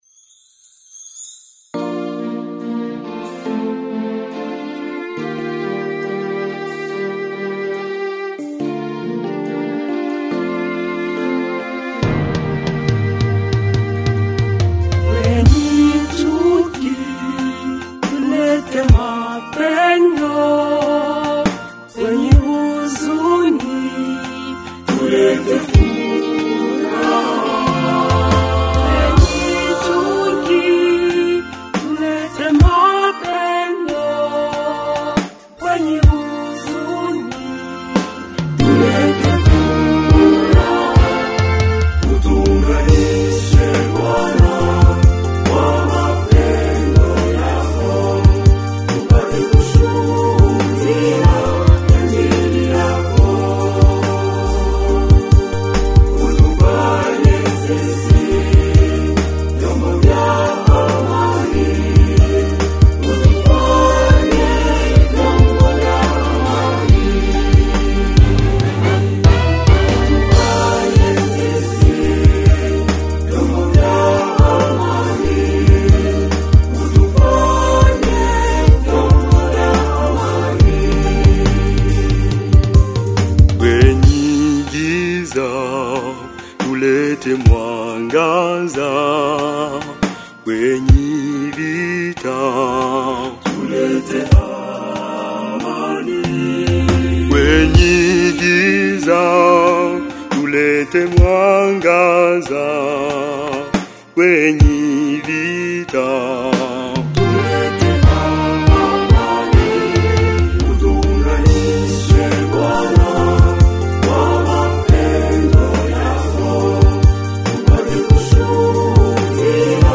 Chants de Méditation Téléchargé par